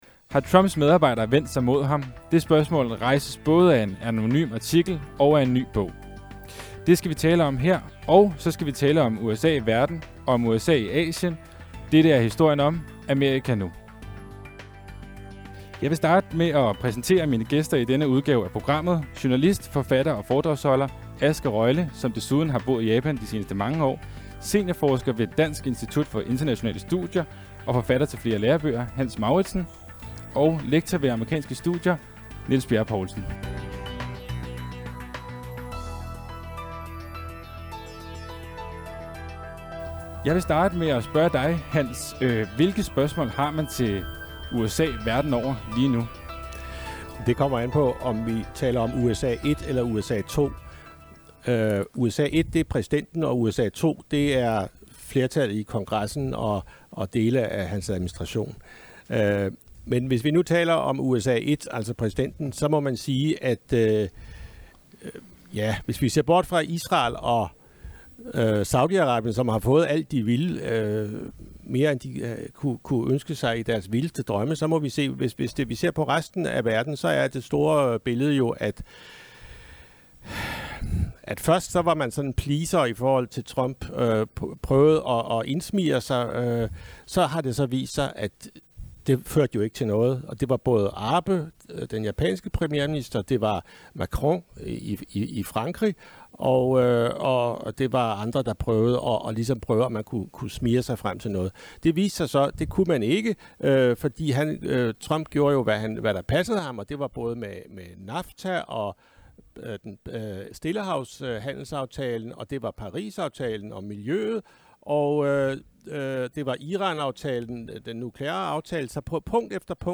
"Amerika Nu #2" blev optaget i Politikens Boghal på Rådhuspladsen fredag 7/9